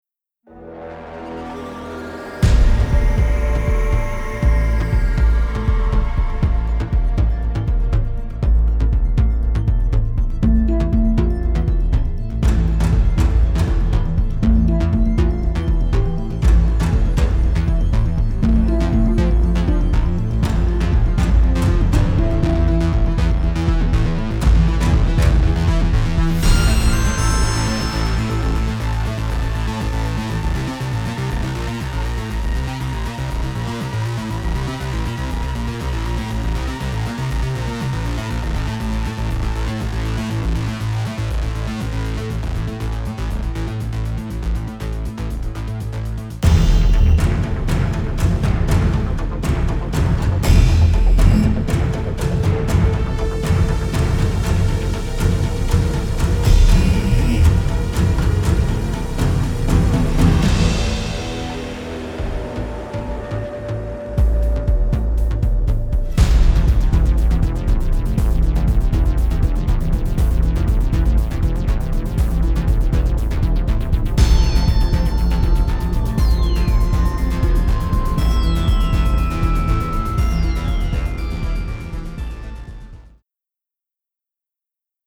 Electronics dominate unusual score